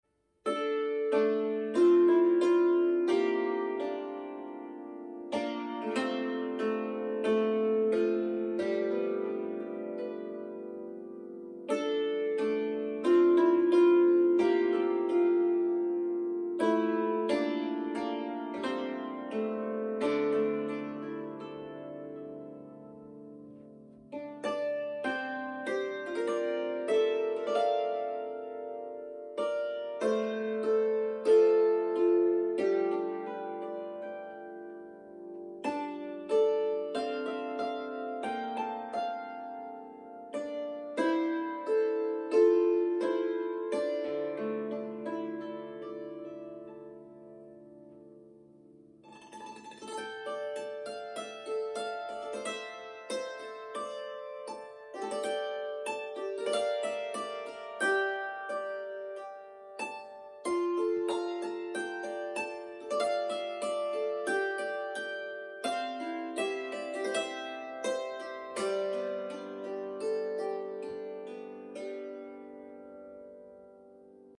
played on hammered dulcimer.